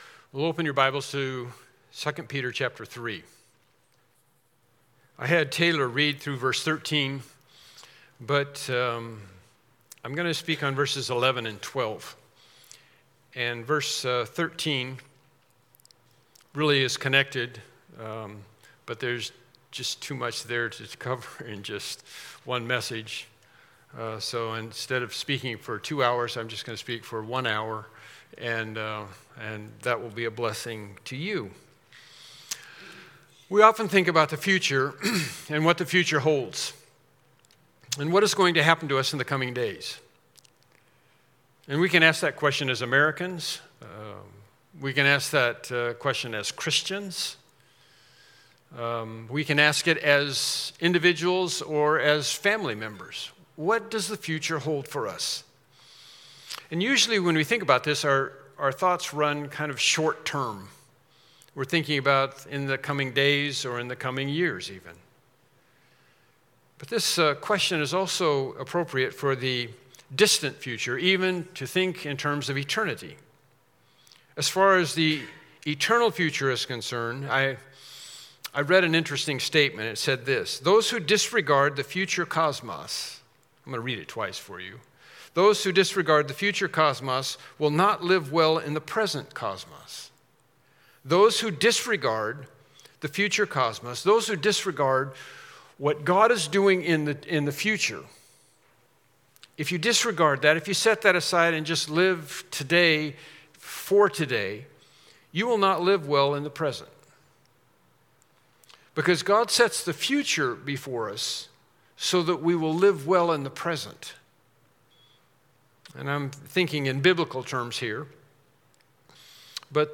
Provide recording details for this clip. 2 Peter 3:11-12 Service Type: Morning Worship Service « Lesson 11